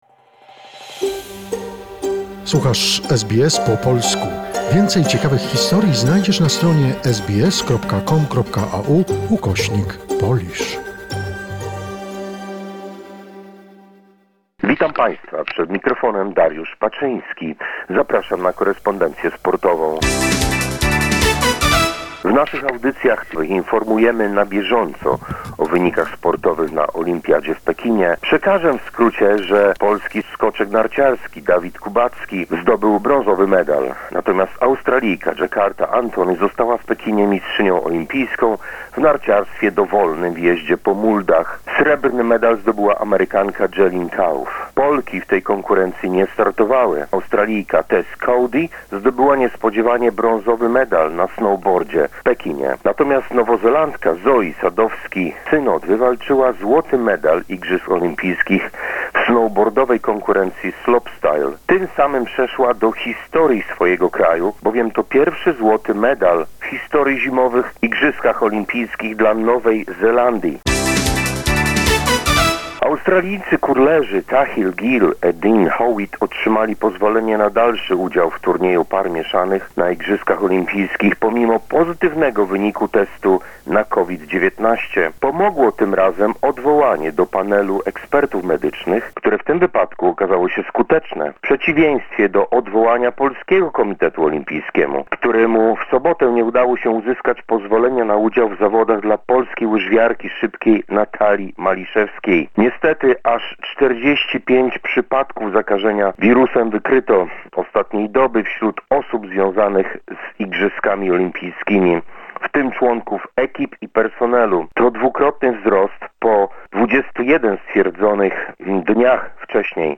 presents Monday sports summary of the week.